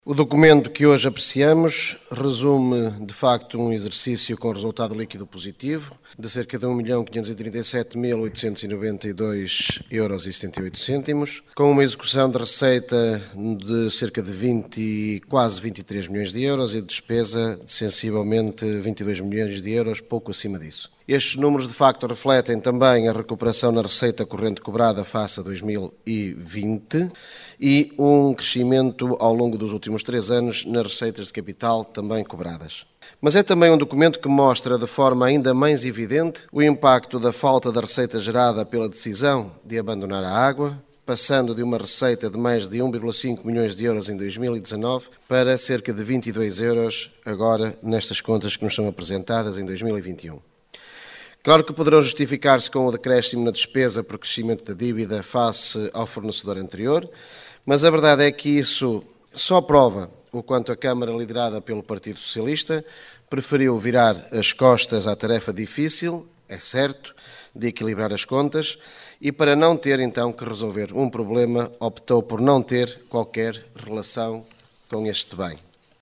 O relatório de contas relativo ao ano de 2021 foi aprovado por maioria na última Assembleia Municipal de Caminha.
Celestino Ribeiro, Coligação Democrática Unitária